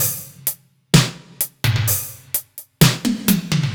Index of /musicradar/french-house-chillout-samples/128bpm/Beats
FHC_BeatC_128-01_NoKick.wav